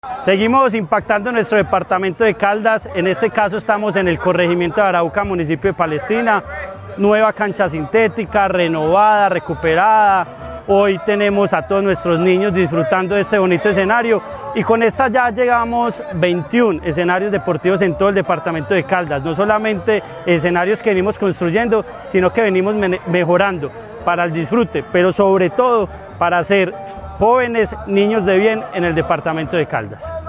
Andrés Duque Osorio, secretario de Deporte, Recreación y Actividad Física de Caldas.
andres-duque-osorio-secretario-de-deporte-de-caldas.mp3